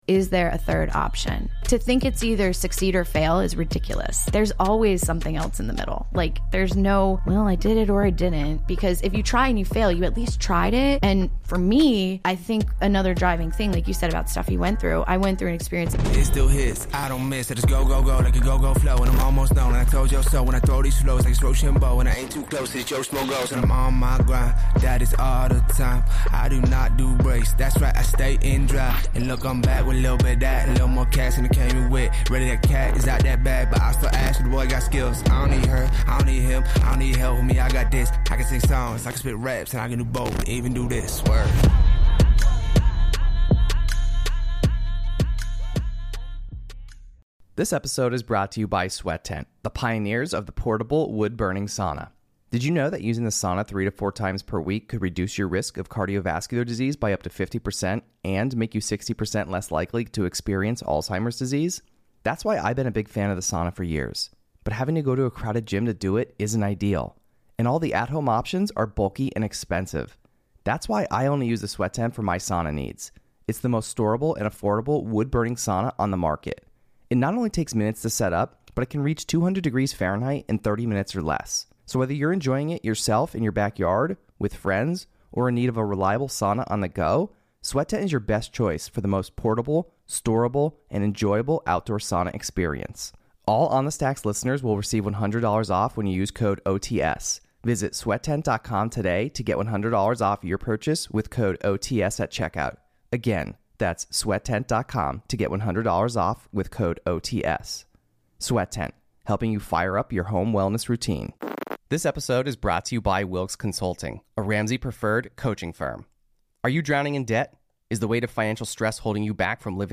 This week in the blu door studio